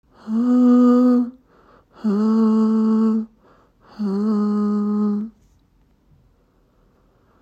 Suchen Sie die Frequenzen, die total röhren und schwächen diese um 10 dB ab.
Stör-Frequenz:
fl-studio-vocals-stoer.mp3